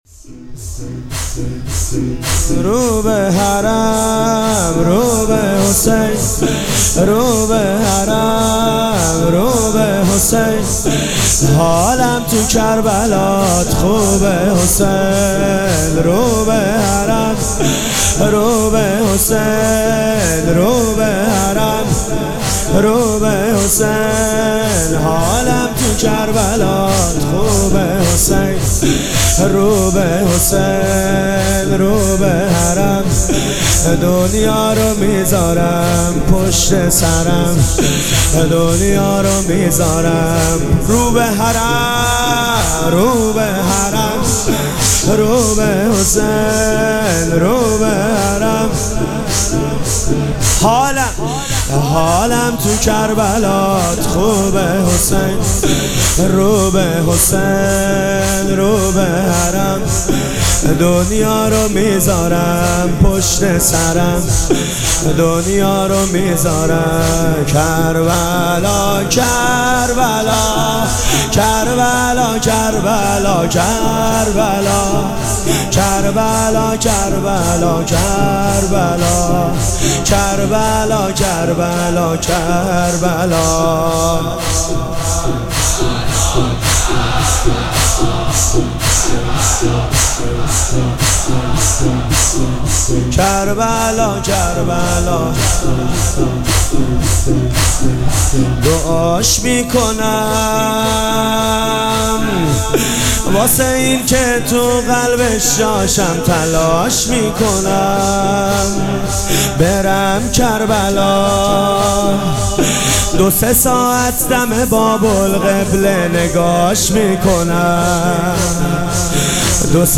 شب چهارم مراسم عزاداری اربعین حسینی ۱۴۴۷
مداح